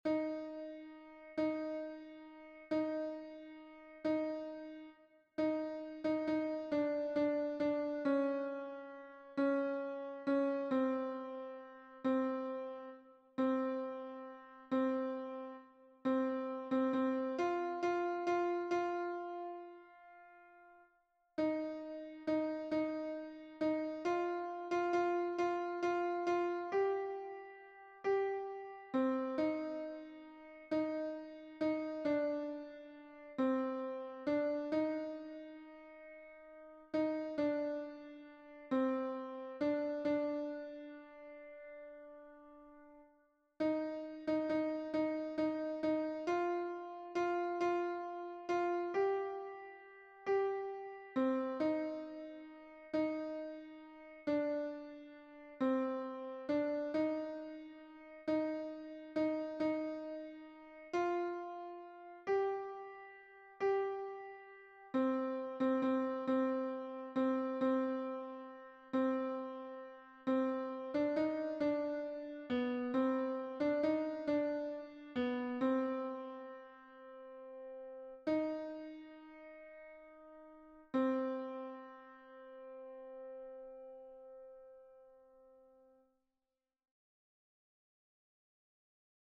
Version piano
Alto